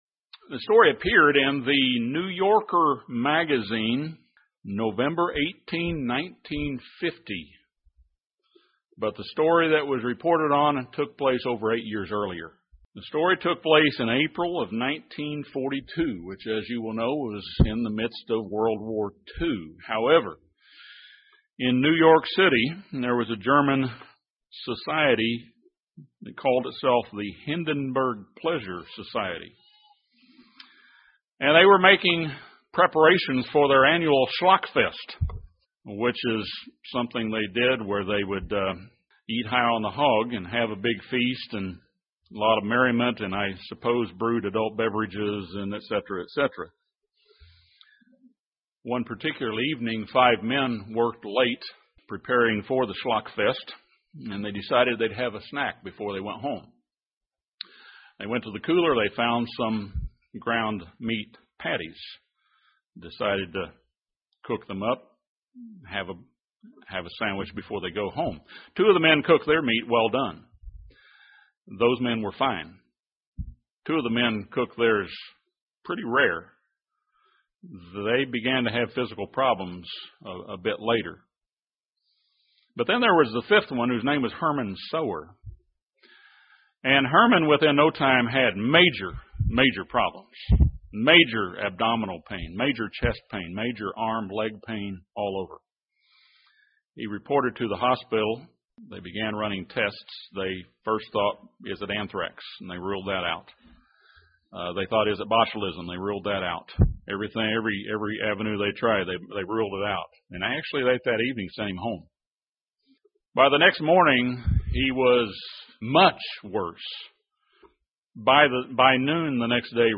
From the early pages of the Bible, God clearly identifies meats that are sanctified for human consumption. In this sermon, we'll see that these laws are unchanged. We will also visit some commonly misconstrued scriptures that many believe prove that God abolished the food laws in New Testament times.